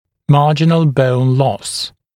[‘mɑːʤɪnl bəun lɔs][‘ма:джинл боун лос]убыль альвеолярной кости